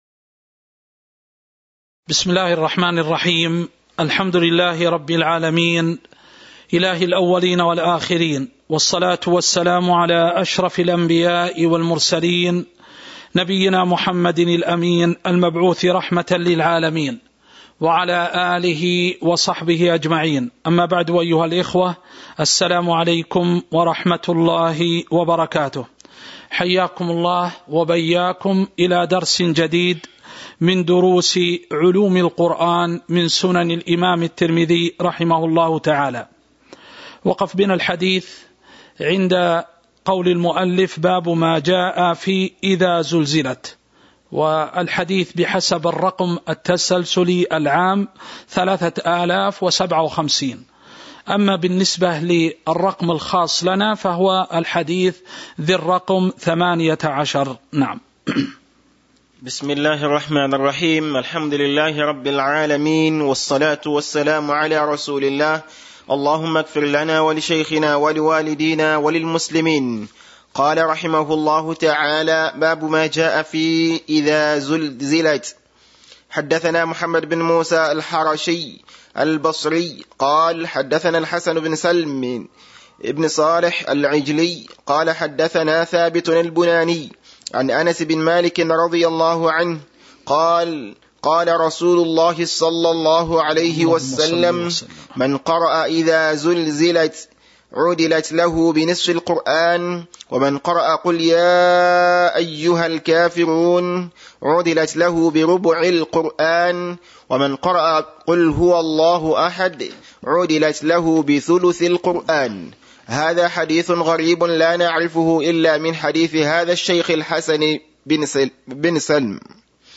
تاريخ النشر ١٤ محرم ١٤٤٣ هـ المكان: المسجد النبوي الشيخ